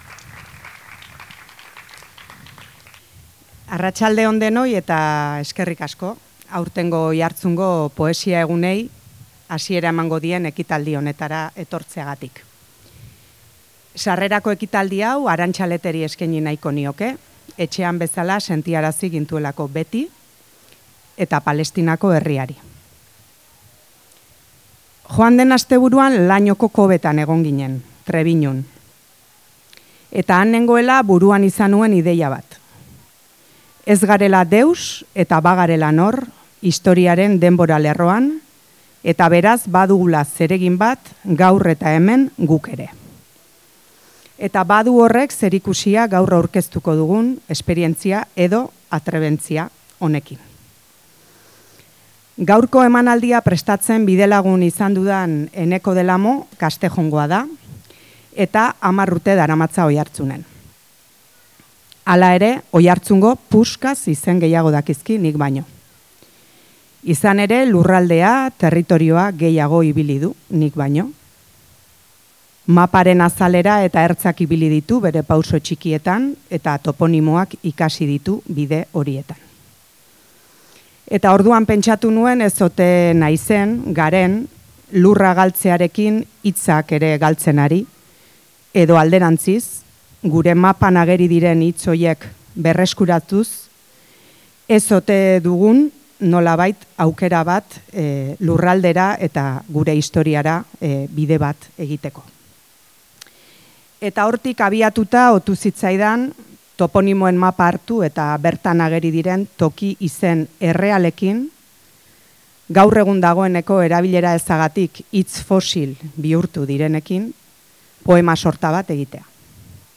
Oiartzungo toponimiaren leku izen errealak abiapuntu hartuta osatutako poema sorta eta herriko hainbat tokitan grabatutako hotsetatik abiatuta sortutako soinu paisaien txatalak bilduz osatutako pieza artistikoa. Herriaren begirada poetiko bat.